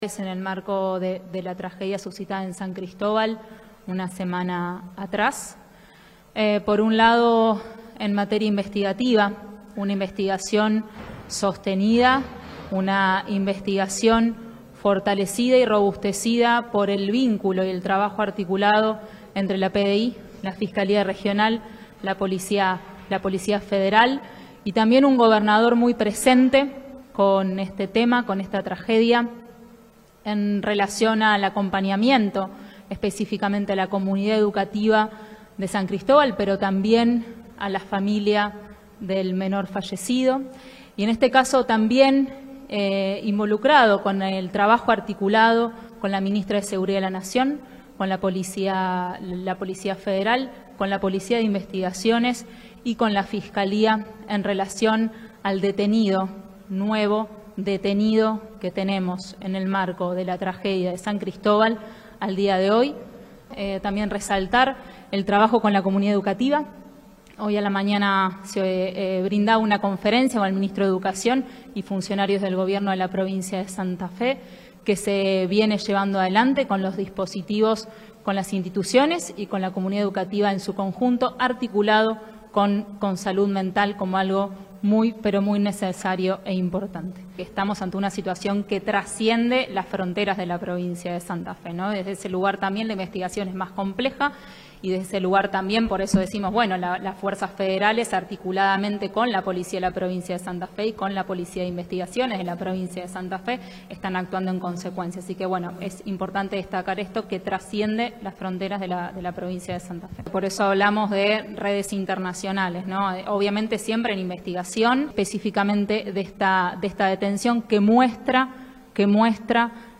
En una conferencia encabezada por la secretaria de Gestión Institucional, Virginia Coudannes, y el director provincial de Investigación Criminal, Rolando Galfrascoli, se informó una nueva detención vinculada al hecho.